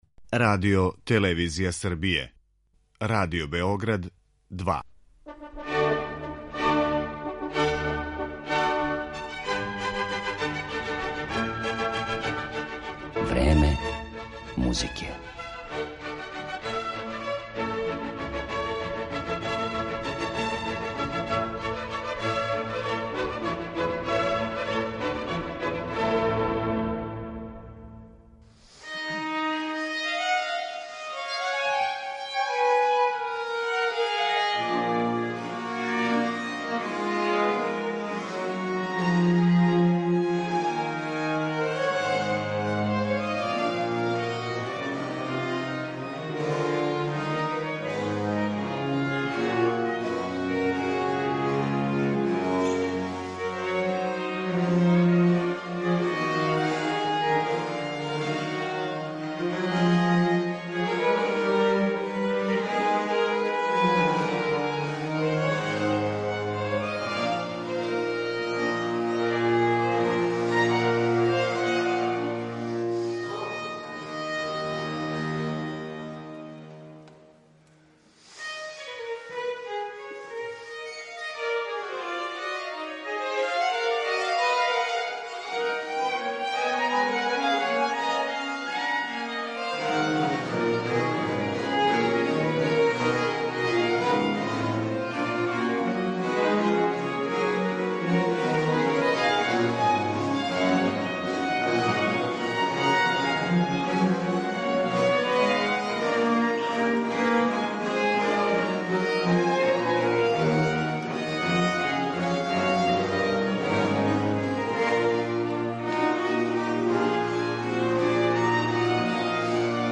Разговарамо у сусрет 20. Београдској барокној академији која се одржава од 17. до 21.јануара и која ове године прославља јубилеј - 10 година постојања.